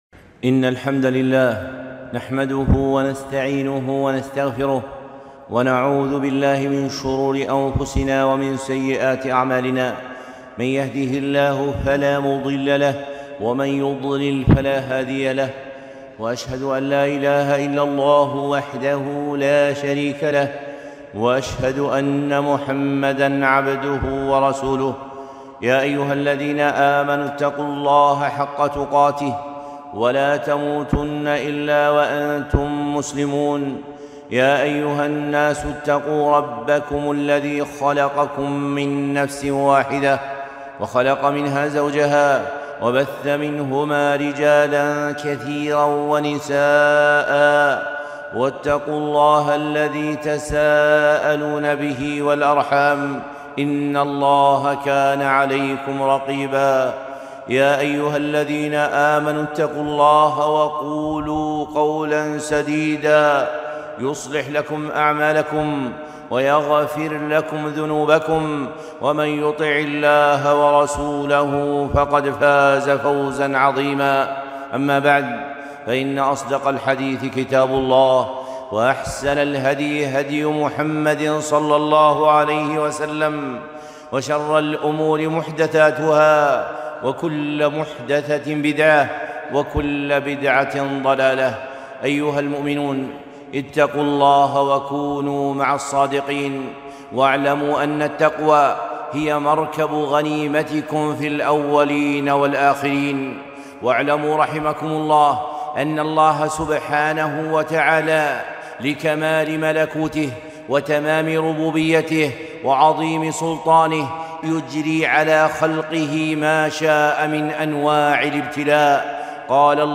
خطبة - امتداد البلاء بوباء كورونا ٢٣ جمادى الأولى ١٤٤٢